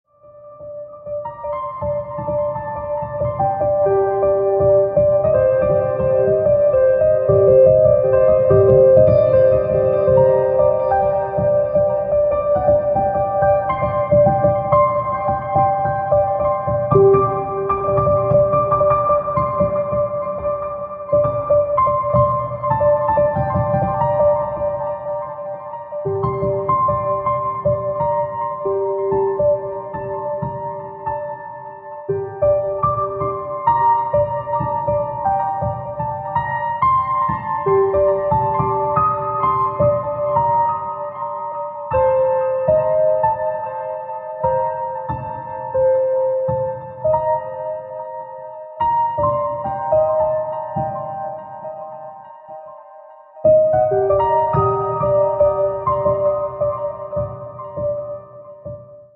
спокойные
без слов
инструментальные
фортепиано